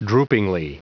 Prononciation du mot droopingly en anglais (fichier audio)
Prononciation du mot : droopingly